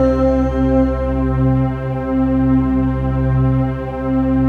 DM PAD2-91.wav